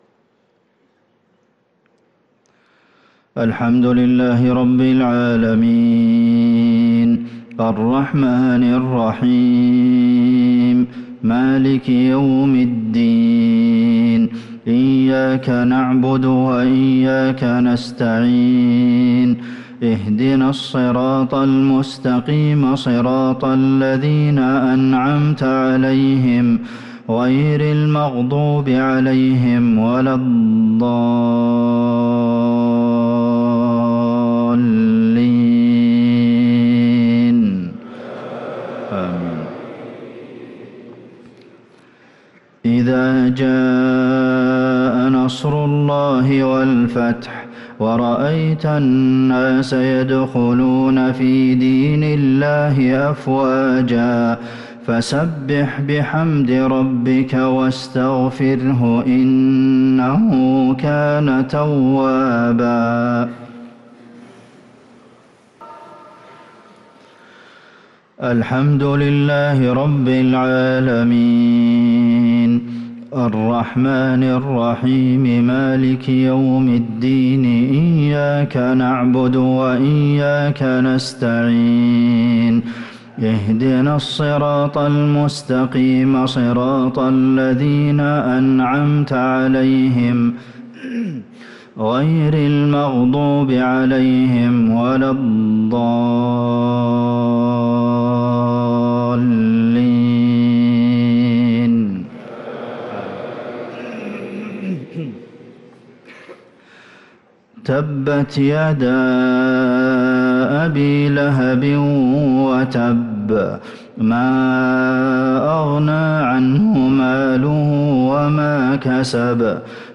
صلاة المغرب للقارئ عبدالمحسن القاسم 8 جمادي الآخر 1445 هـ
تِلَاوَات الْحَرَمَيْن .